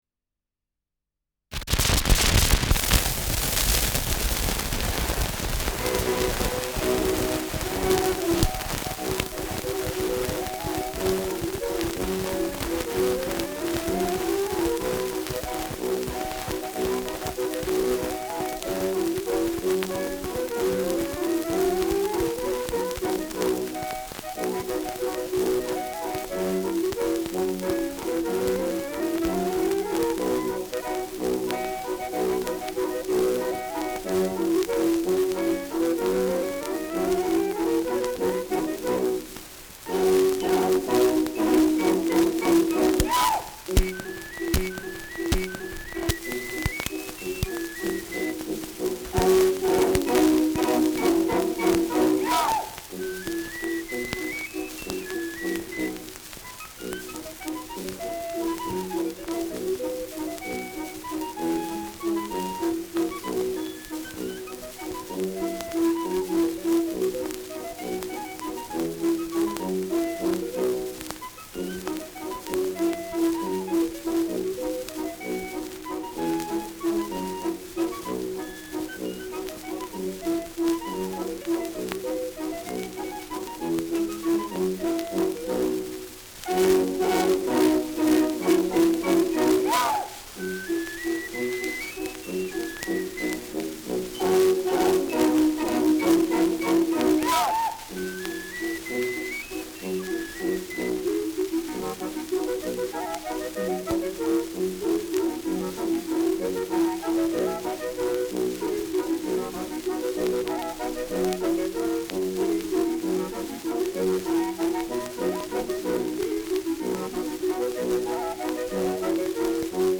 Schellackplatte
Stark verrauscht : Sprung zu Beginn : Stark abgespielt : Hängt im ersten Drittel : Vereinzelt leichtes Knacken
Militärmusik des k.b. Leib-Regiments, München (Interpretation)
[München] (Aufnahmeort)